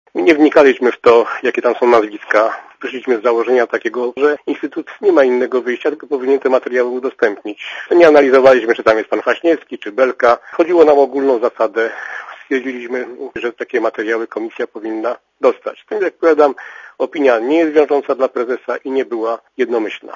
* Dla Radia ZET mówi Sławomir Radoń*